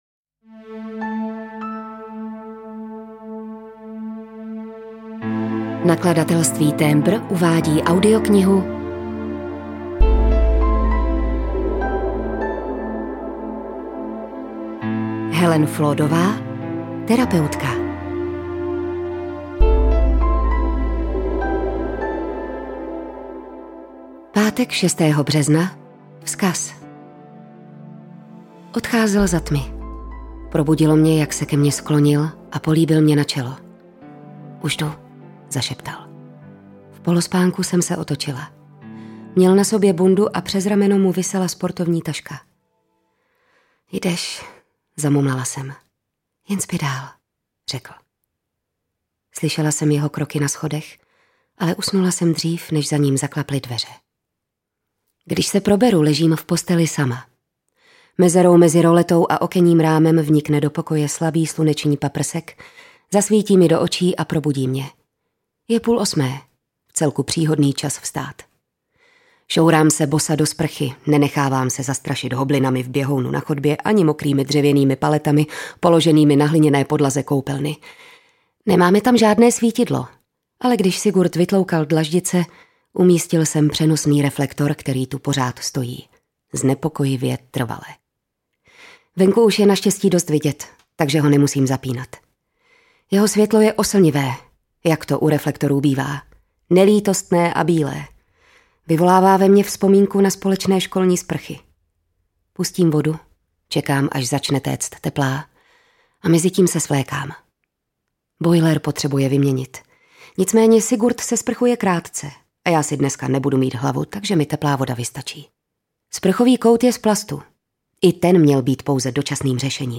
Terapeutka audiokniha
Ukázka z knihy